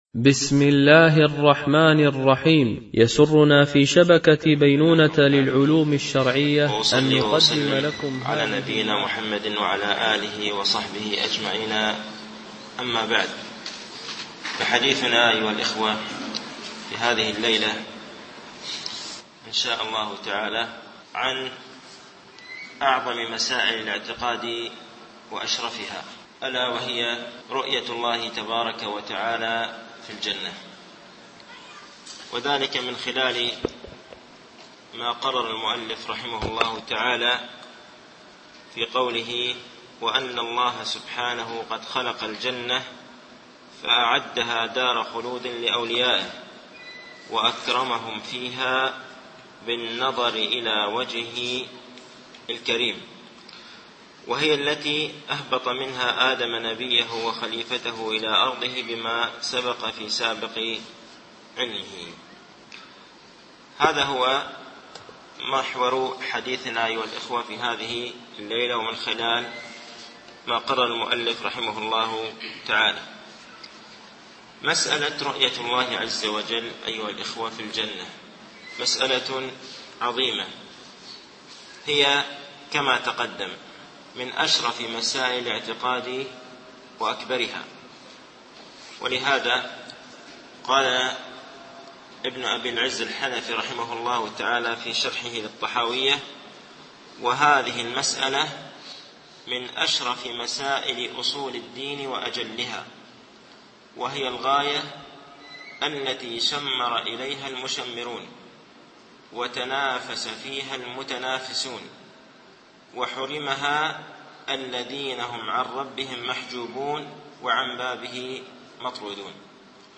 شرح مقدمة ابن أبي زيد القيرواني ـ الدرس الثاني و الأربعون